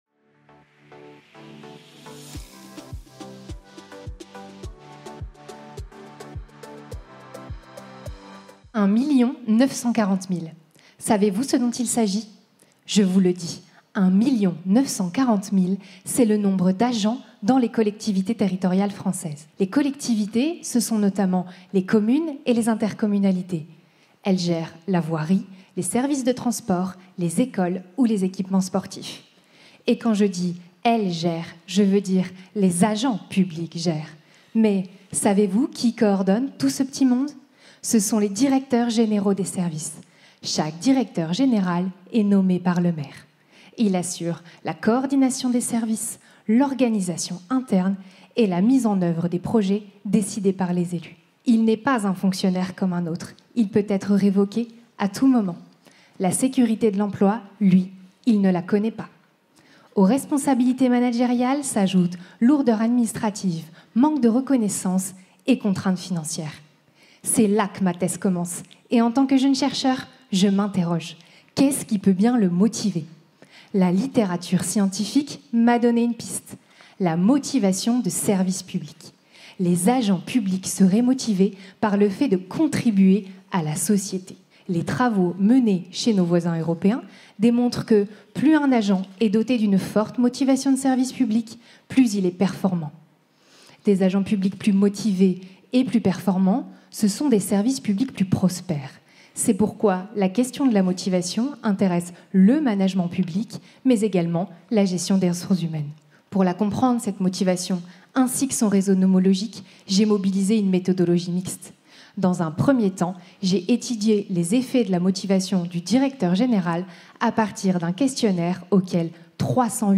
Pitch pour le Prix FNEGE de la Meilleure Thèse en Management 2024 (thèse en 180 secondes) – Prix de thèse AIRMAP Les collectivités territoriales françaises sont gérées par les Directeurs Généraux des Services (DGS).